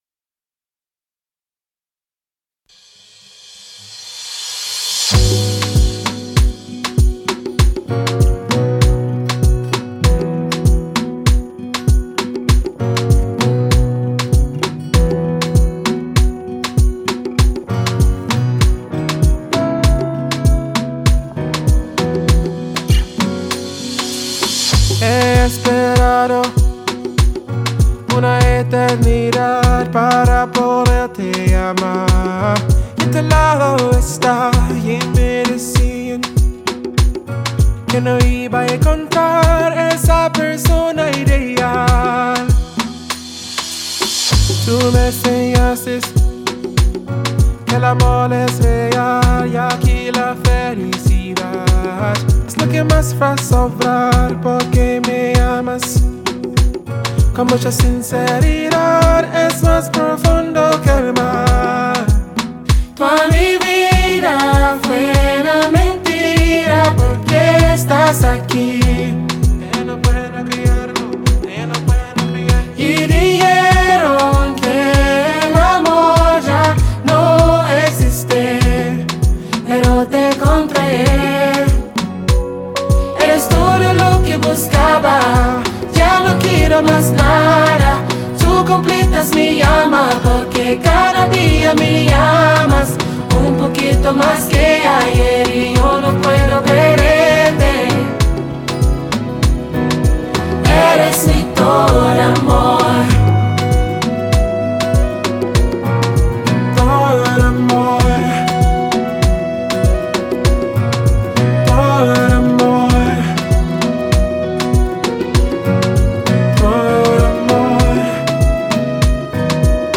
Nigerian alternative singer